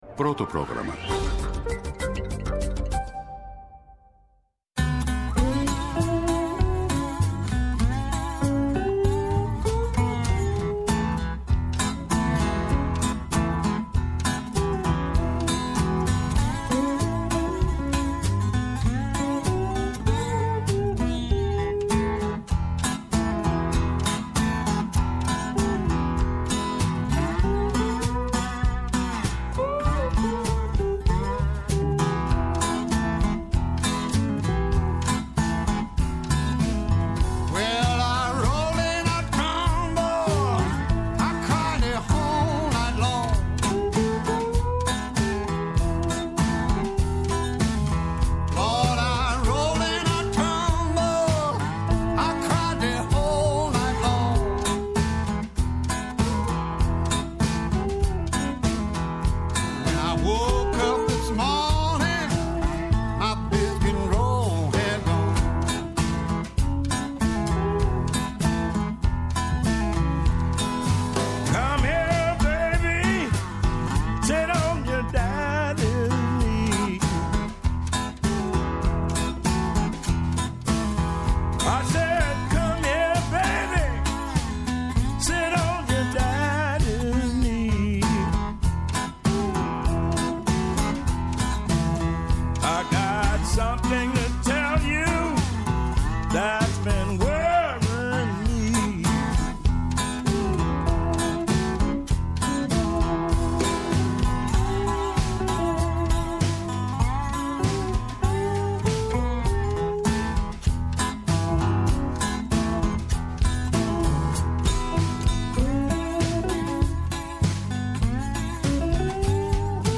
Άνθρωποι της επιστήμης, της ακαδημαϊκής κοινότητας, πολιτικοί, ευρωβουλευτές, εκπρόσωποι Μη Κυβερνητικών Οργανώσεων και της Κοινωνίας των Πολιτών, συζητούν για όλα τα τρέχοντα και διηνεκή ζητήματα που απασχολούν τη ζωή όλων μας, από την Ελλάδα και την Ευρώπη μέχρι την άκρη του κόσμου. ΕΡΤNEWS RADIO